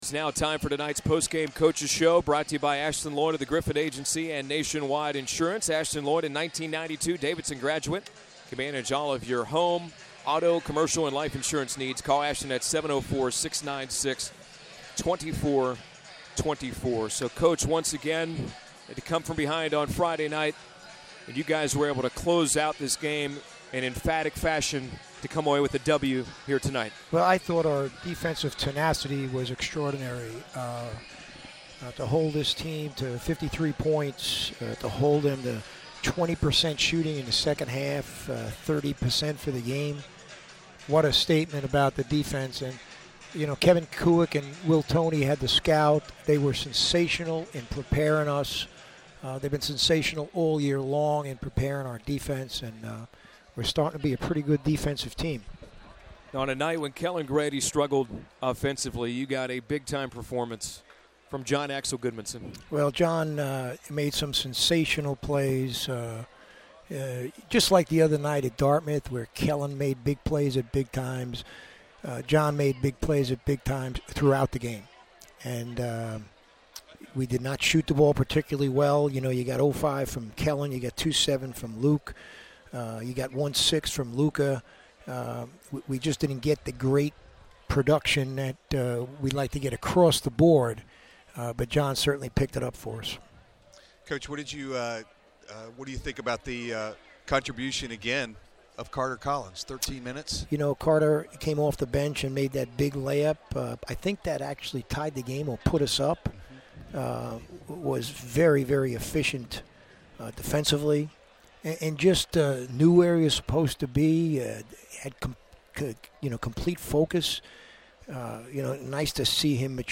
Postgame Interview